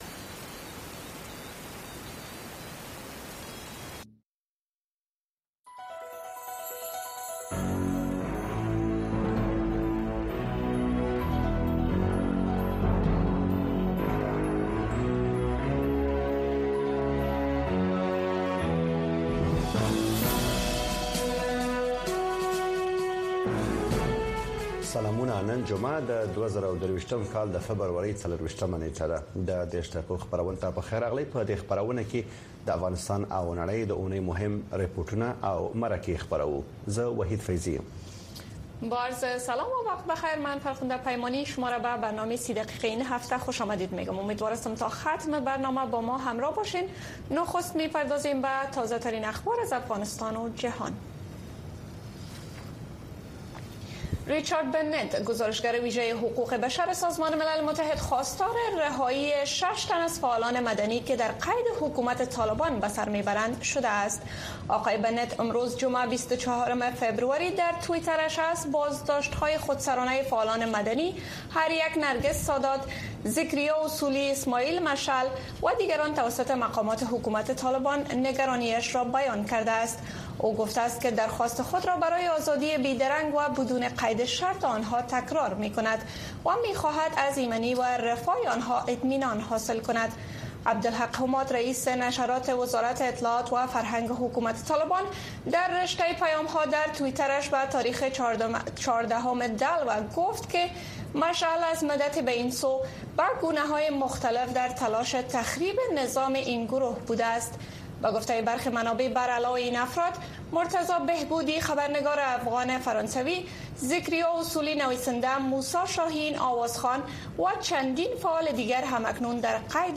د ۳۰ دقیقې په اونیزه خپرونه کې د اونۍ مهمو پیښو، رپوټونو او مرکو ته بیاکتنه کیږي او د افغانستان د ورځنیو پیښو په اړه تازه او هر اړخیرې ارزونې وړاندې کیږي.